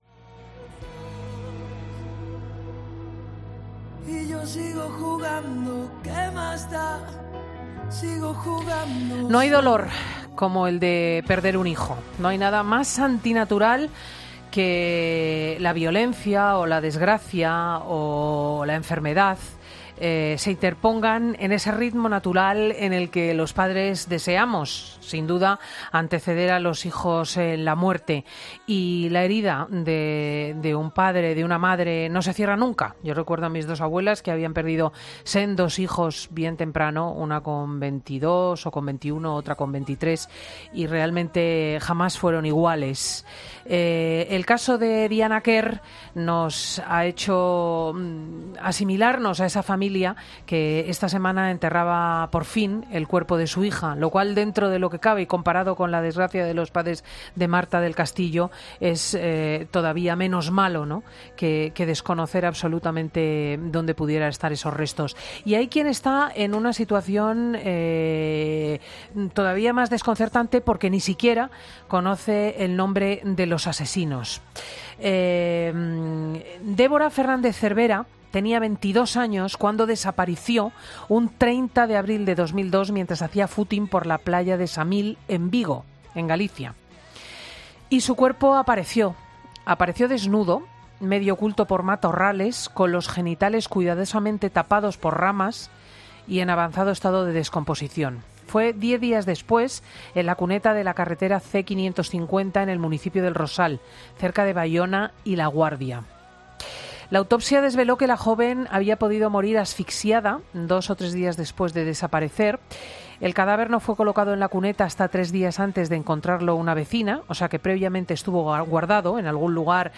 ESCUCHA LA ENTREVISTA COMPLETA EN 'FIN DE SEMANA' La familia de la joven cree que hay elementos en común con el caso de Diana Quer.